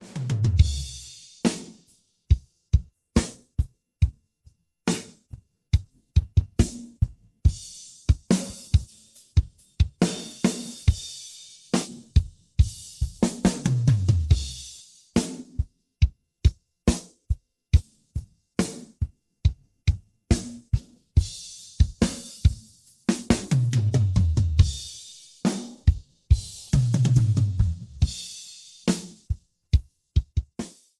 続いて、同じカラオケの伴奏の「ドラムのみ」↓↓↓